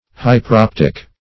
Hy`per*op"tic, a.
hyperoptic.mp3